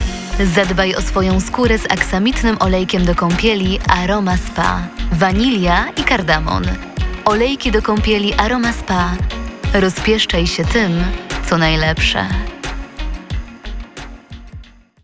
Udzielam głosu do nagrań lektorskich – audiobooki, teksty medytacyjne, reklamy, filmy instruktażowe, dokumentalne i fabularne.